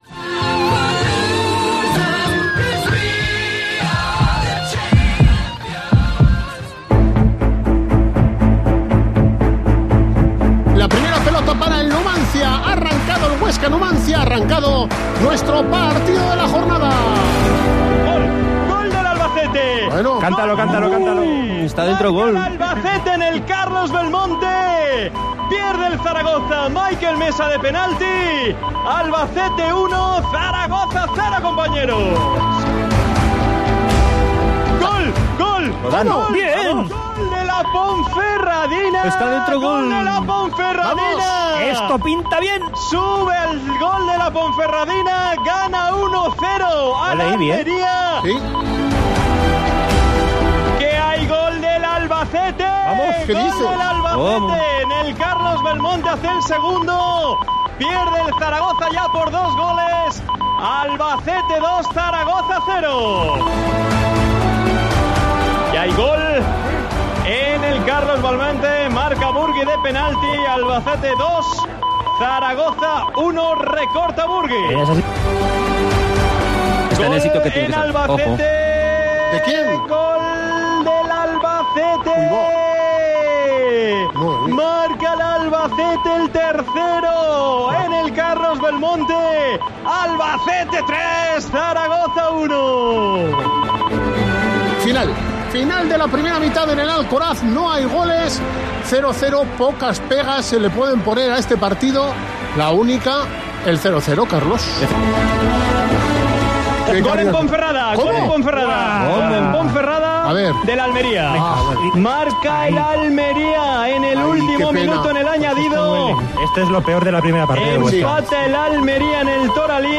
Así sonó el ascenso de la SD Huesca en COPE Huesca